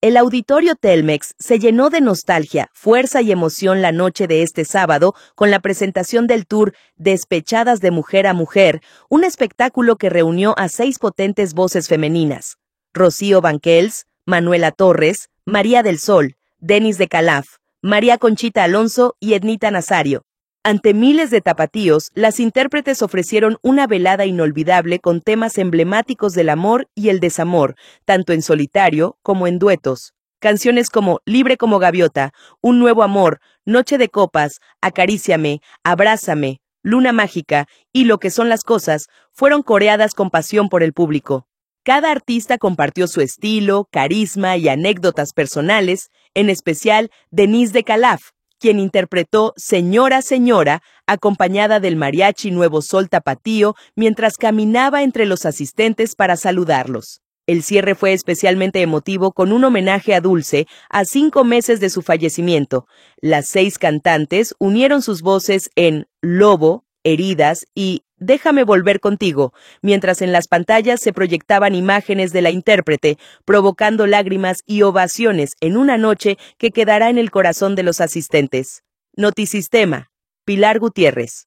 audio El Auditorio Telmex se llenó de nostalgia, fuerza y emoción la noche de este sábado con la presentación del tour Despechadas: De Mujer a Mujer, un espectáculo que reunió a seis potentes voces femeninas: Rocío Banquells, Manoella Torres, María del Sol, Denisse de Kalafe, María Conchita Alonso y Ednita Nazario.
concierto-1.m4a